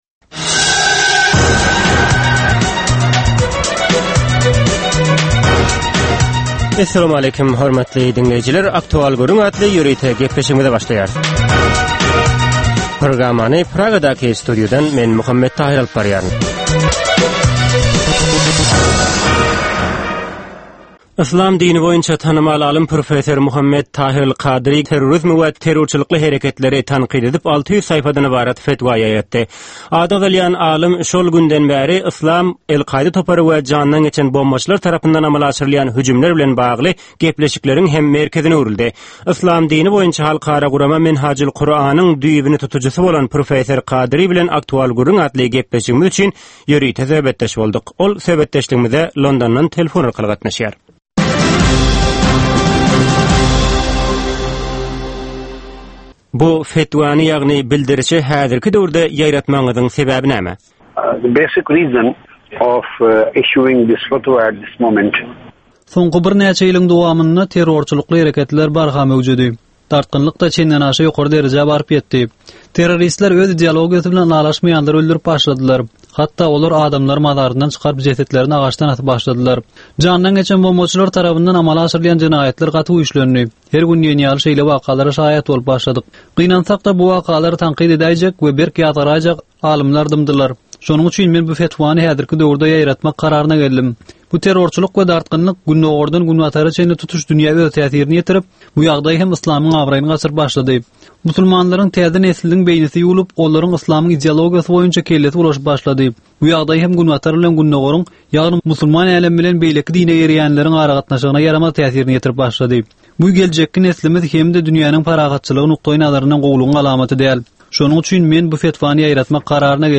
Hepdäniň dowamynda Türkmenistanda ýa-da halkara arenasynda ýüze çykan, bolup geçen möhüm wakalar, meseleler barada anyk bir bilermen ýa-da synçy bilen geçirilýän 10 minutlyk ýörite söhbetdeşlik. Bu söhbetdeşlikde anyk bir waka ýa-da mesele barada synçy ýa-da bilermen bilen aktual gürrüňdeşlik geçirilýär we meseläniň dürli ugurlary barada pikir alyşylýar.